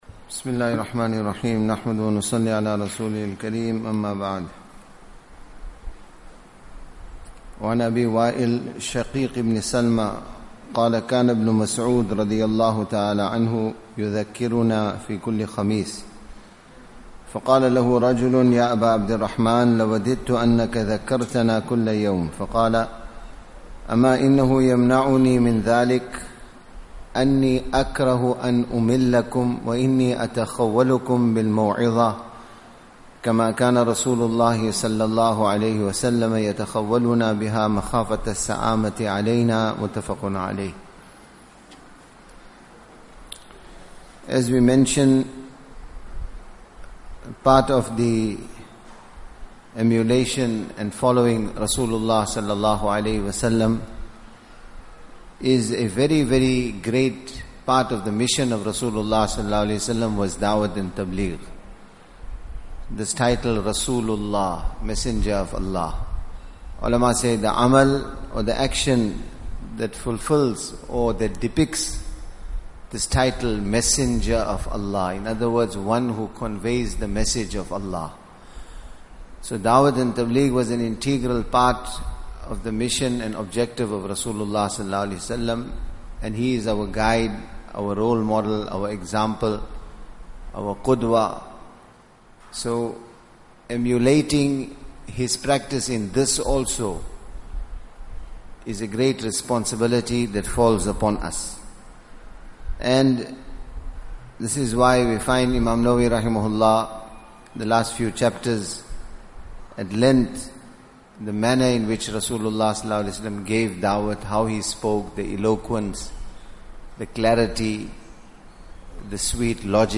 Morning Discourses